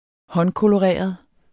Udtale [ ˈhʌnkoloˌʁεˀʌð ]